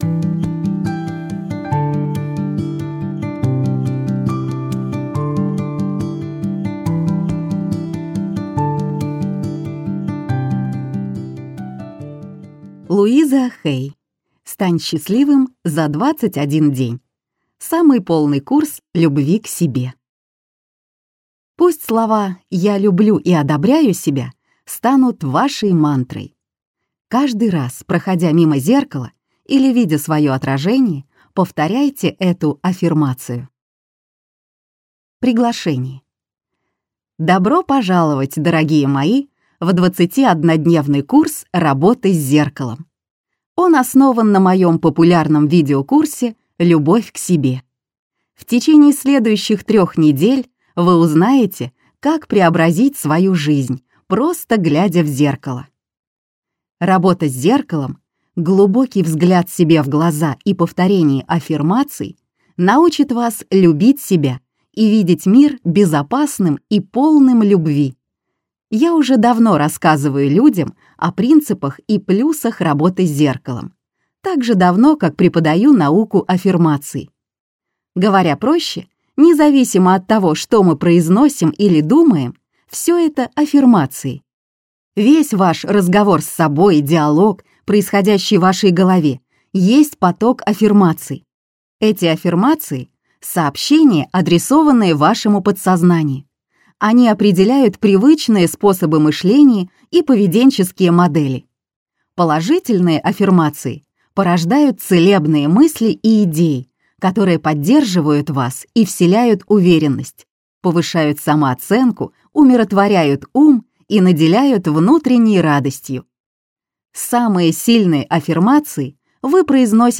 Аудиокнига Стань счастливым за 21 день. Самый полный курс любви к себе - купить, скачать и слушать онлайн | КнигоПоиск